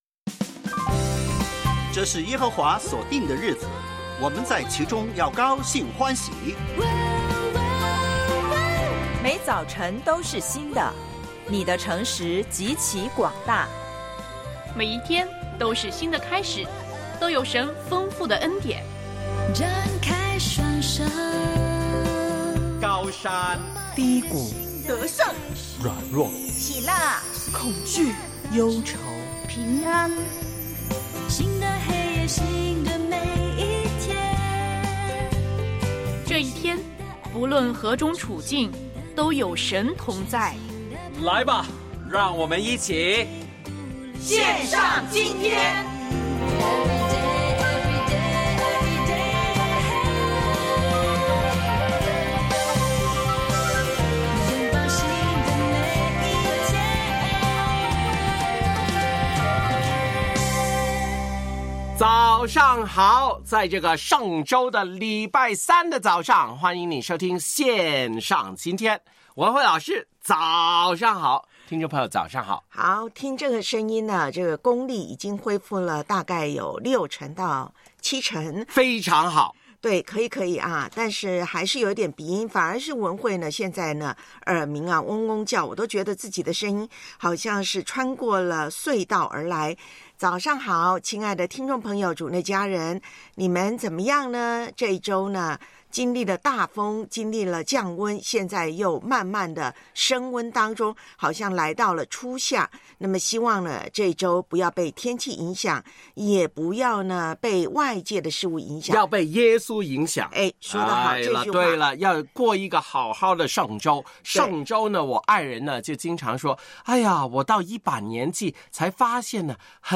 教会年历灵修：约翰福音13:21-32；复活节广播剧（3）耶稣复活；我爱背金句：撒母耳记上7:12